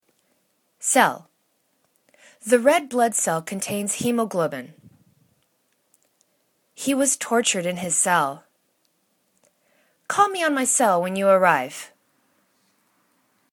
cell  /sel/  [C]